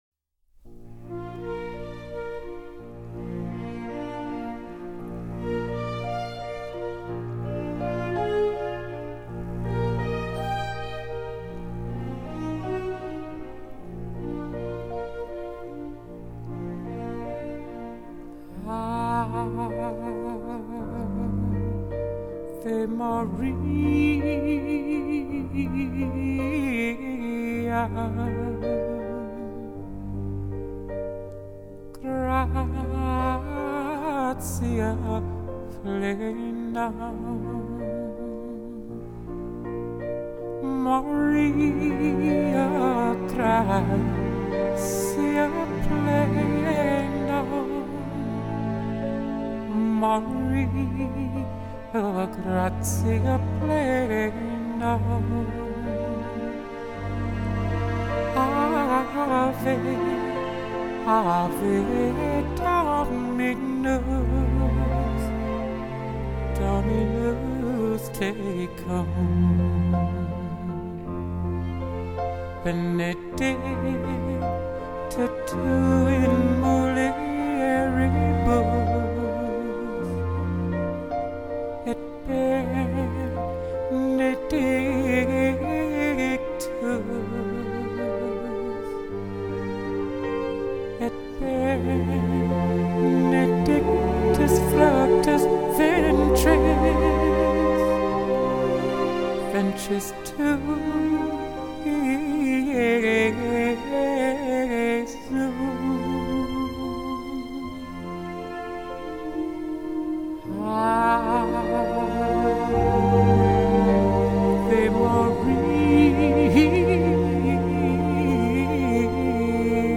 类型：R&B
旋律是优美和缓的起伏，象6级风下的小波浪，承托你疲惫的心，如一叶蚱蜢舟， 不载一丝忧愁，且尽情地在无边的空灵里优游吧。
那个特别的声音